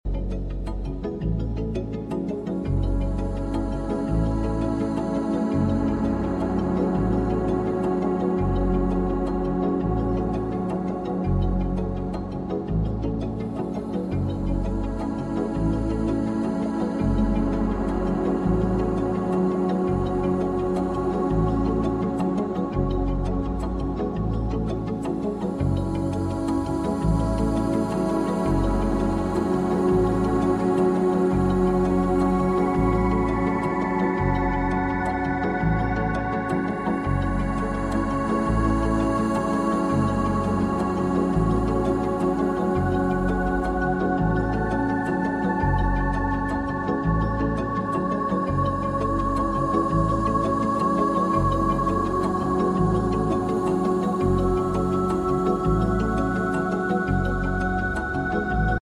1126 hz